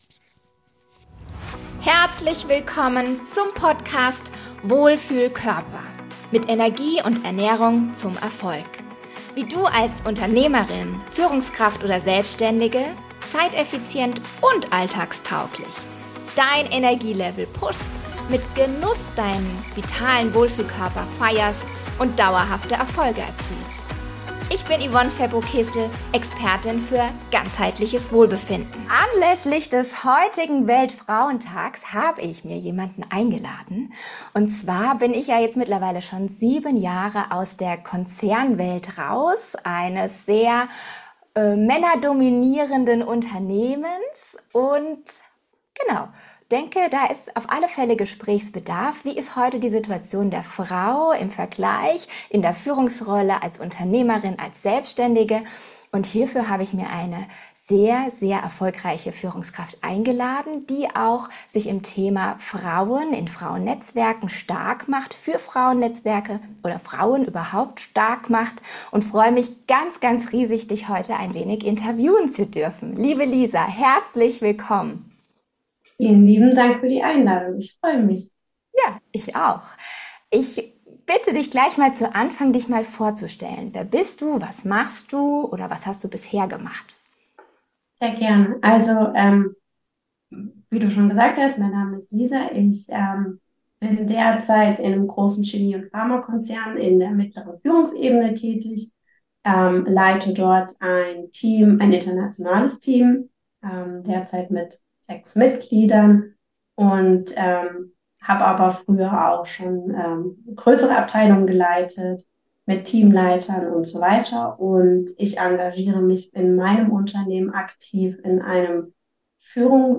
In dieser Folge wird eine sehr erfolgreiche Führungskraft ihre Erfahrungen aus Business und Privatleben erläutern und wichtige Gedanken für die zukünftige Handhabung aufzeigen.